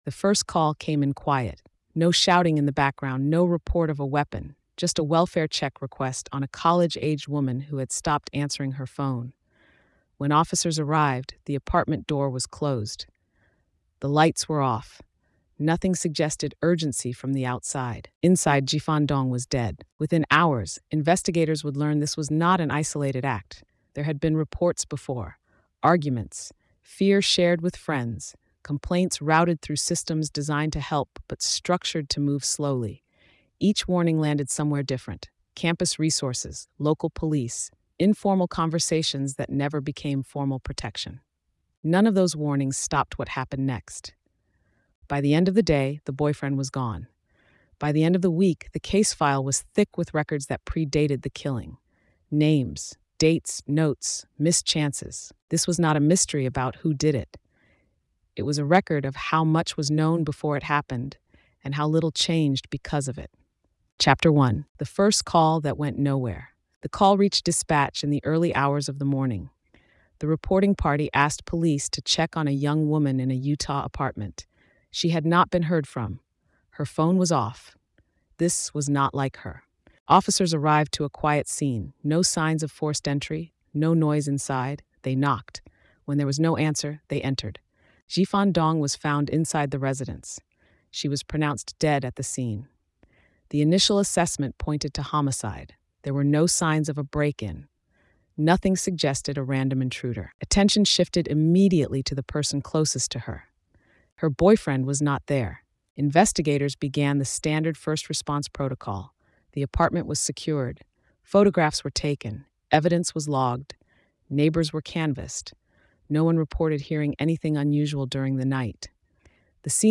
Told in a neutral, investigative voice, the story traces the final days, the warning signs that were documented but fragmented across systems, and the aftermath that followed her death. It is not a mystery about who committed the crime, but a case study in how institutional gaps, procedural limits, and isolated decision-making allowed a visible risk to escalate unchecked.